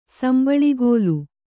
♪ sambaḷigōlu